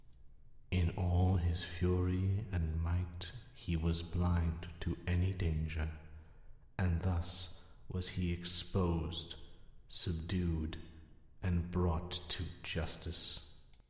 So, today, I thought I’d share a few takes for a few characters I auditioned for.